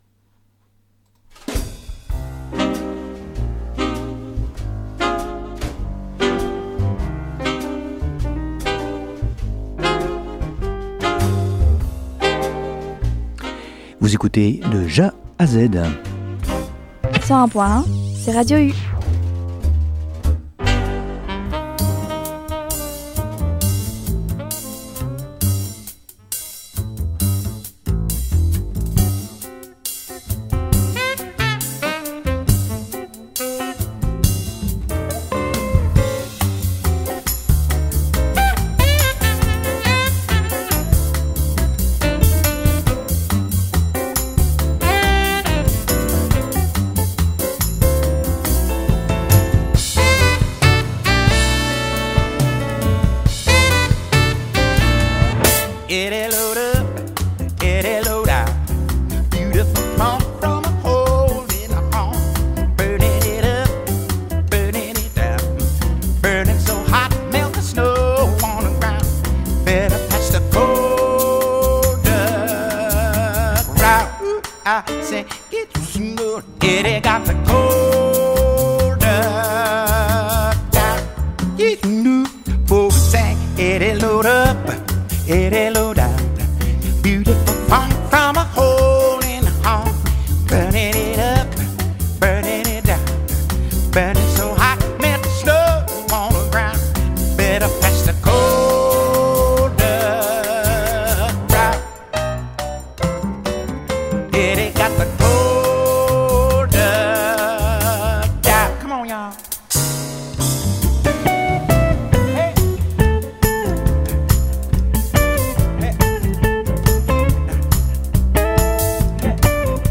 Retrouvez ma sélection de jazz du mois